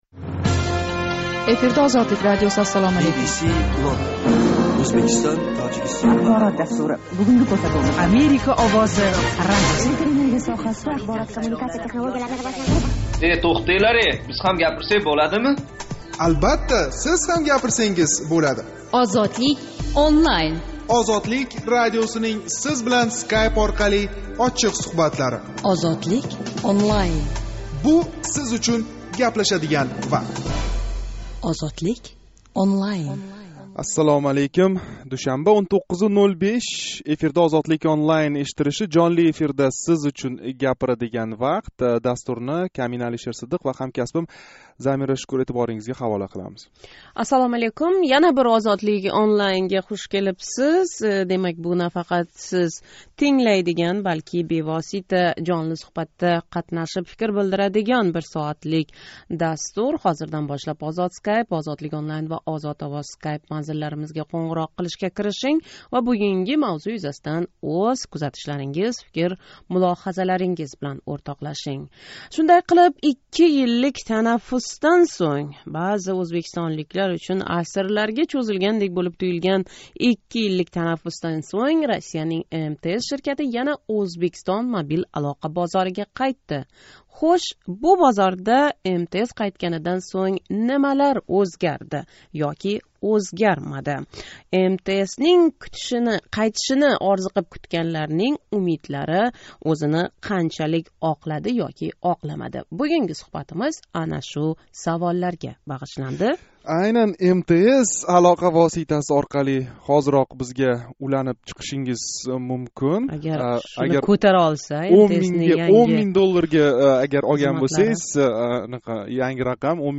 МТСнинг қайтиши шу умидларни оқладими? 8 декабрь кунги жонли суҳбатда шу ҳақда гаплашамиз.